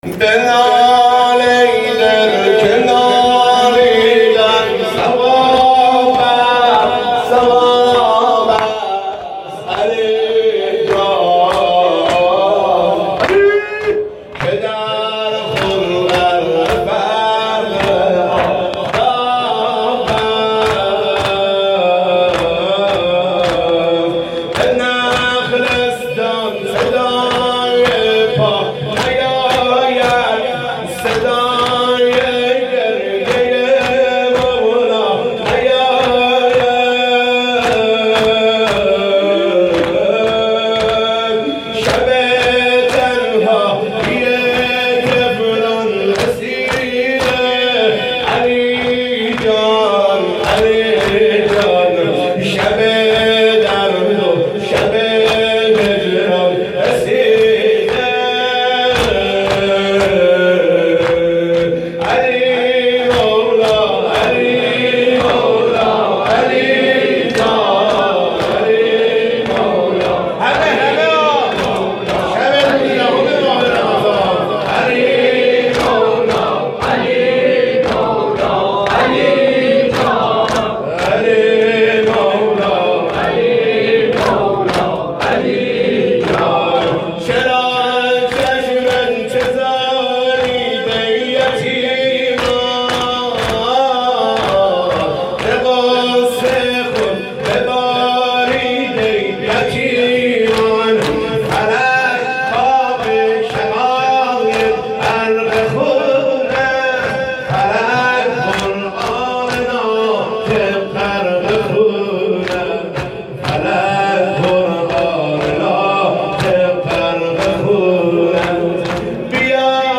هیئت جان نثاران جوادالائمه (مصعبی های مقیم مشهد)
شب های قدر 96
مداحی